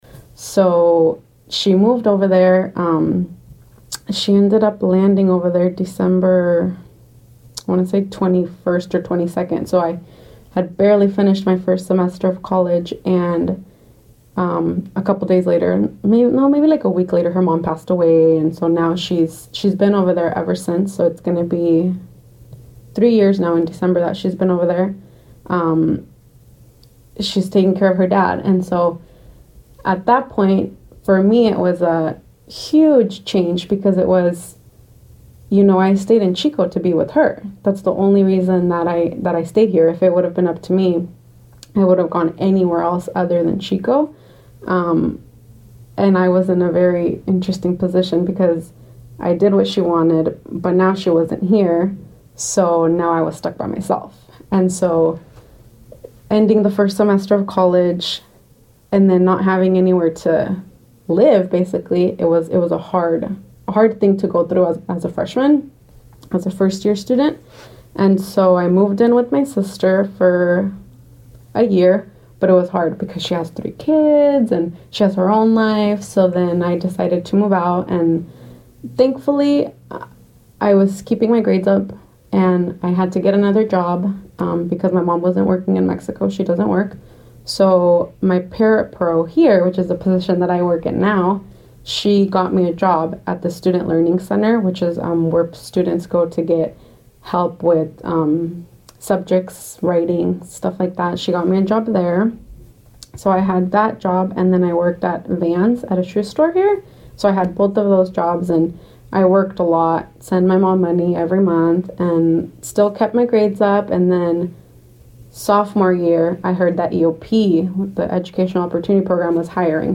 Going places resources Crossroads 9 C9_the real thing CD1 15 On My Own 2.mp3 CD1 15 On My Own 2.mp3 Album: Unknown Sjanger: Rhythmic Soul Format: MPEG Audio Lydformat: MPEG-1 Audio layer 3 CD1 15 On My Own 2.mp3